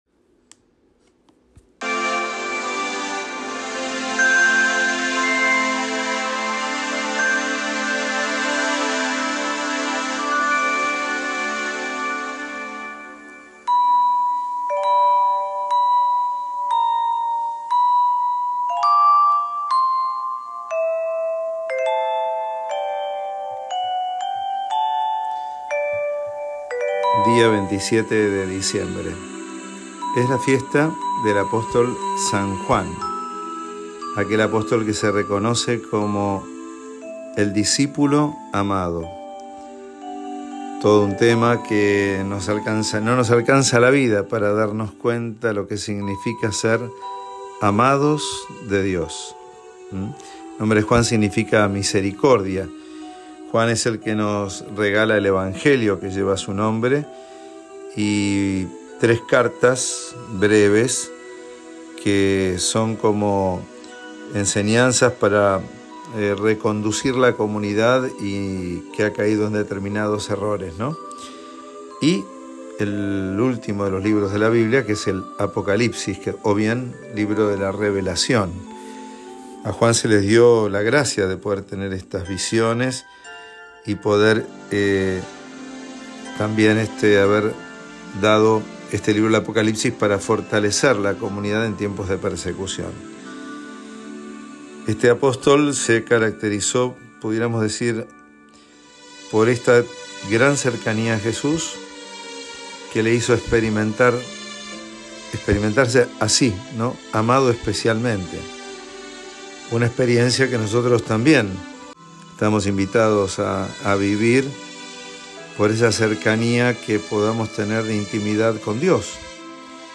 Homilía Evangelio según san Juan 20